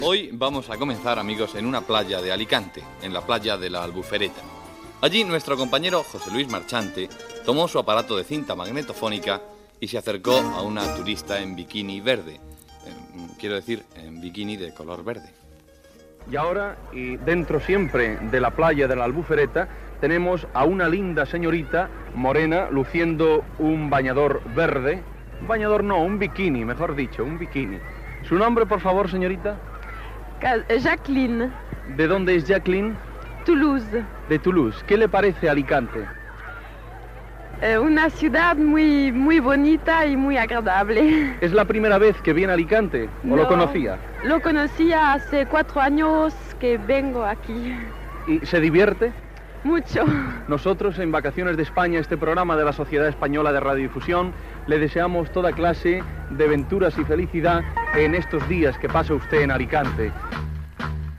Diàleg humorístic del locutor amb una turista alemanya.